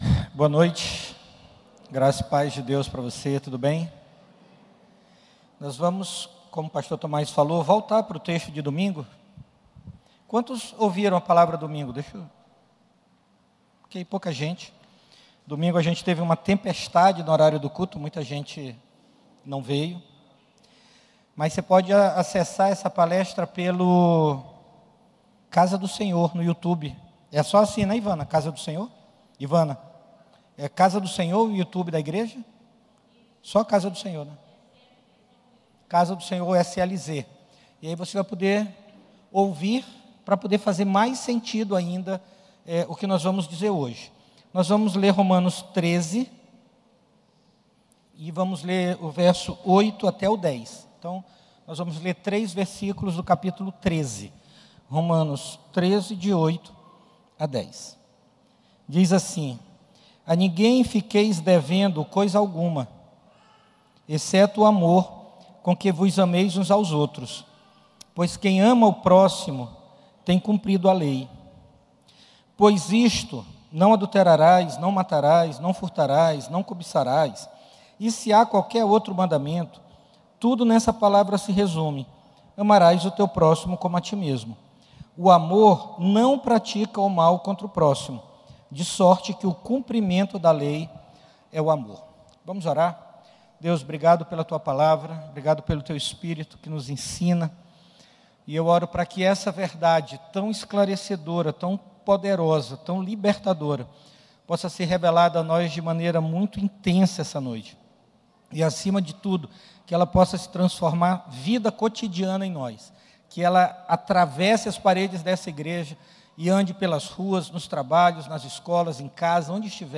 Culto presencial aos domingos às 18 h.